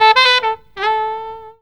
LIGHT RIFF.wav